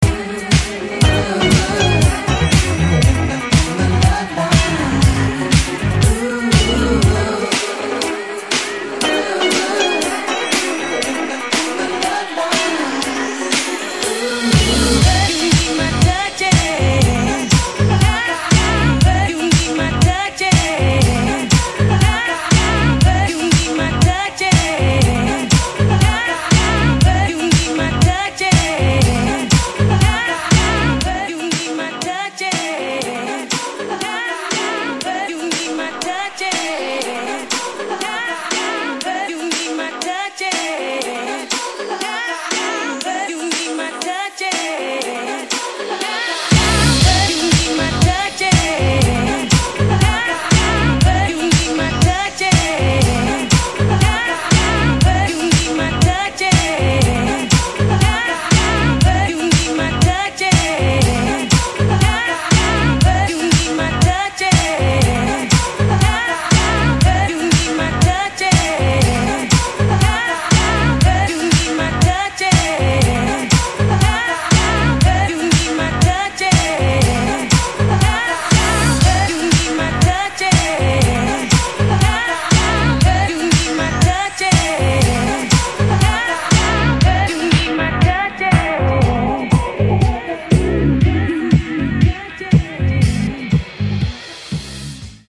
4 danceable boogie disco re-edits